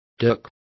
Complete with pronunciation of the translation of dirks.